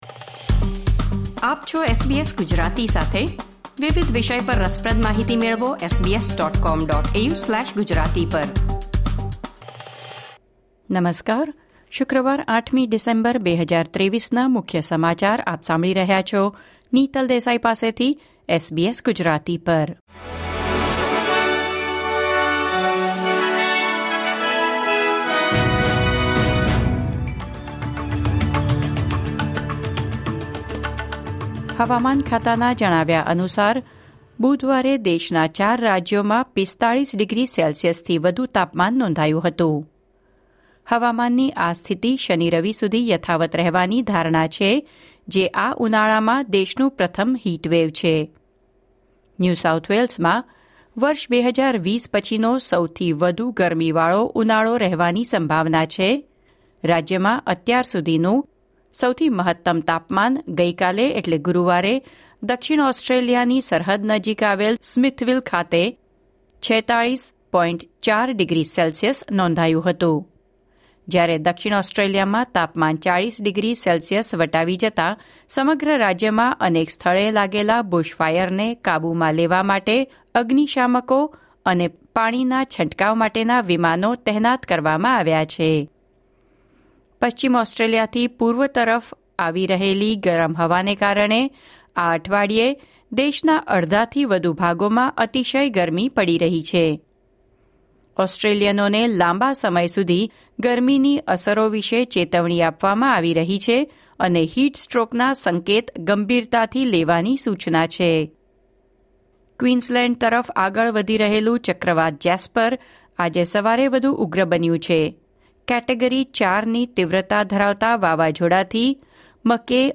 SBS Gujarati News Bulletin 8 December 2023